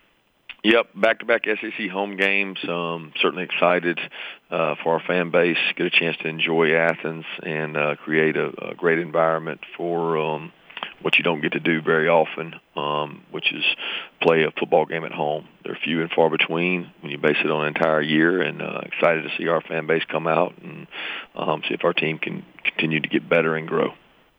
UGA’s head coach Kirby Smart comments on the home advantage in the SEC teleconference.
kirby-smart-home-game.wav